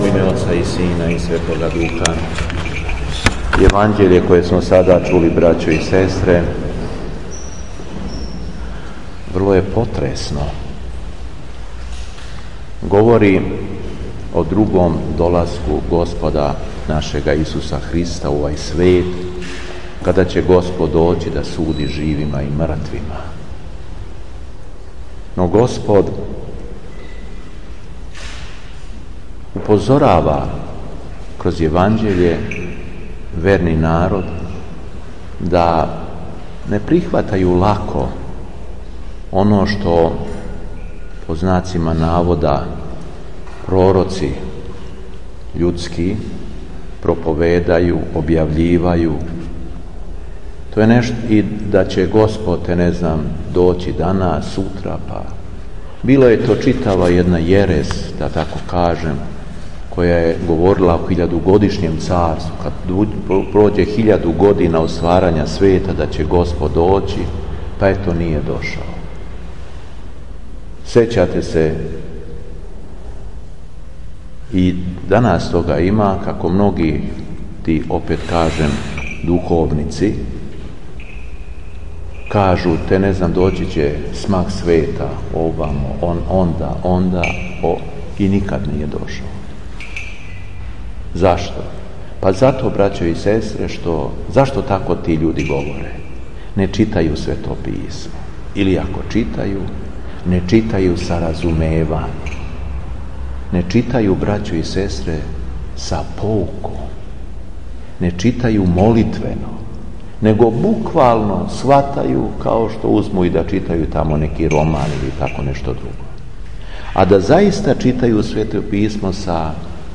Беседа Епископа шумадијског Г. Јована
У петак, једанаести по Духовима, Његово Преосвештенство Епископ шумадијски Господин Јован, служио је Свету Архијерејску Литургију у храму Свете Петке у Виноградима.